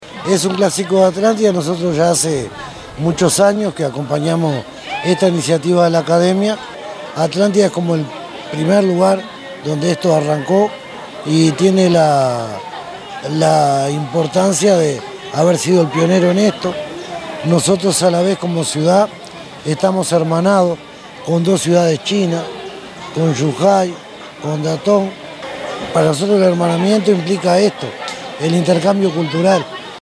alcalde_gustavo_gonzalez.mp3